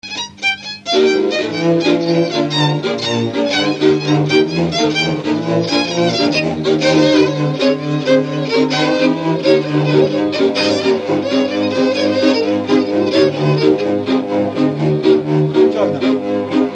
Dallampélda: Hangszeres felvétel
Erdély - Szolnok-Doboka vm. - Szék
hegedű
kontra
bőgő
Stílus: 7. Régies kisambitusú dallamok